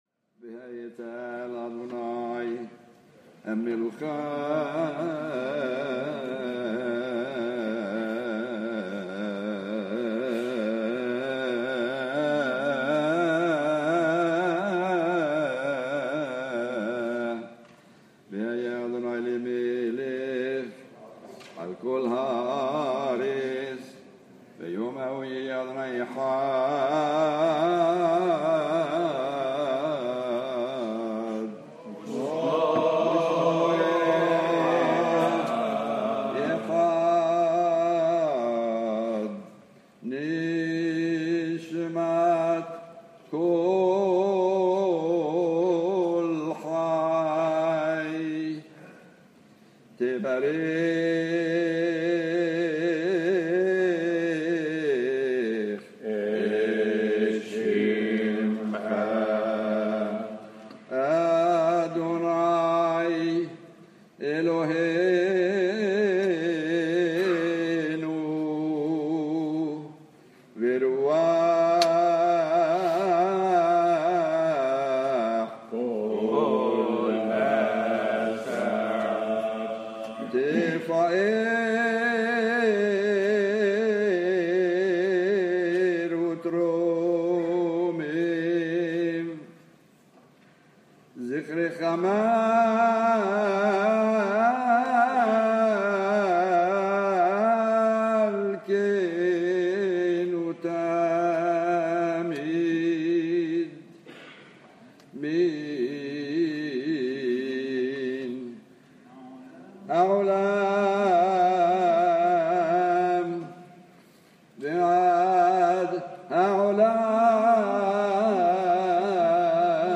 Maqam Hijaz